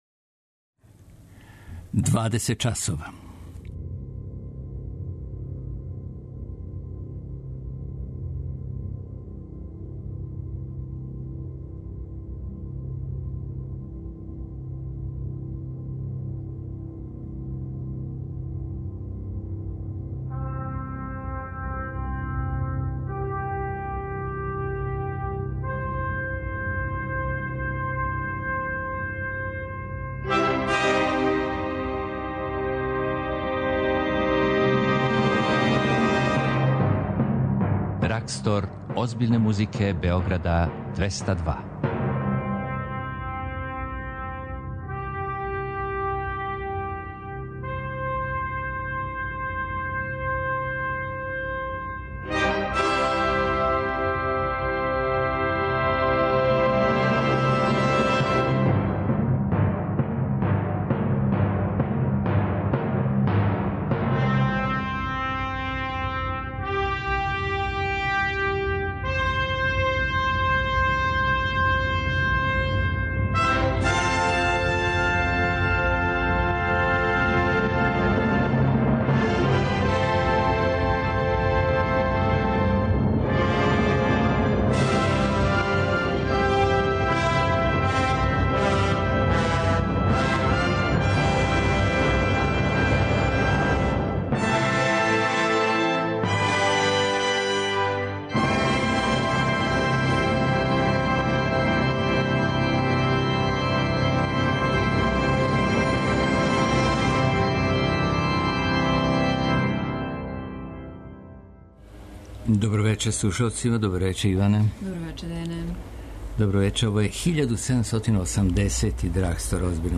Култна емисија Београда 202 која промовише класичну музику.